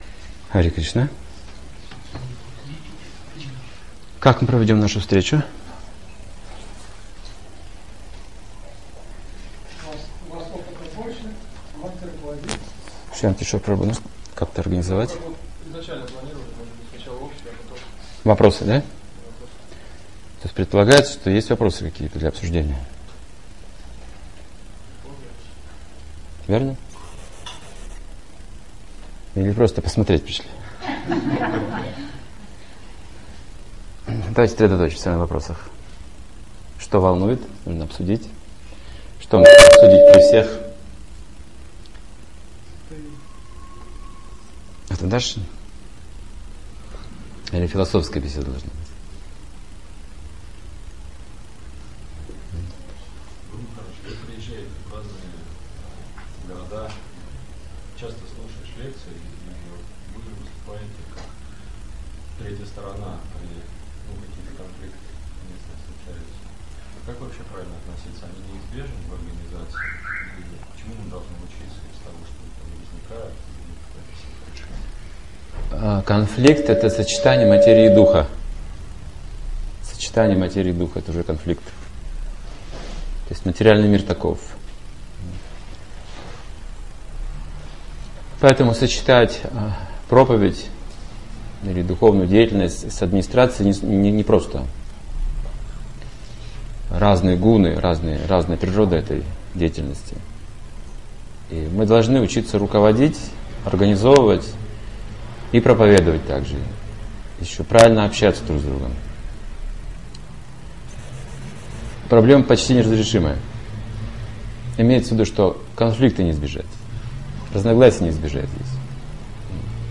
Беседа в рамках вопросов-ответов: о духовном общении, преданном служении, прогрессе и трудностях в практике Сознания Кришны.